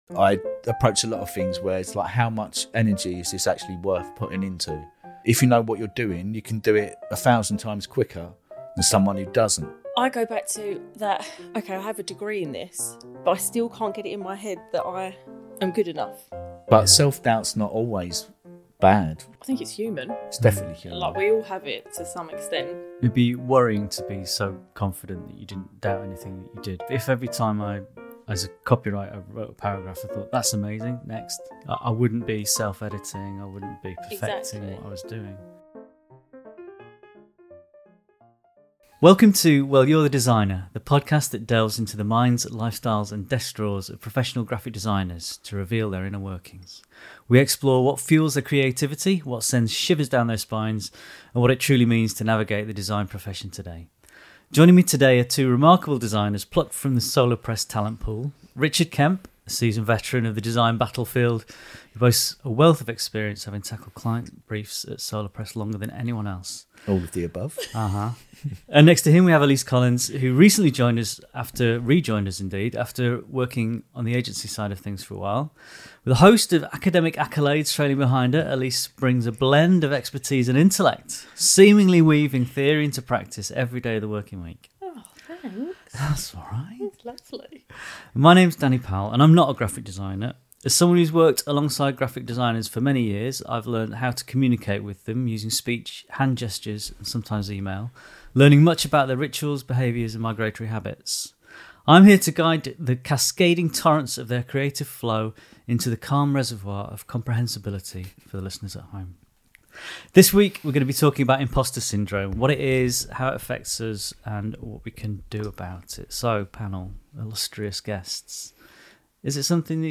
In the subjective world of graphic design where everyone has an opinion, how do we summon the confidence to trust our abilities and experience? Our panel certainly isn’t immune to Imposter Syndrome.